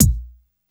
kick 05.wav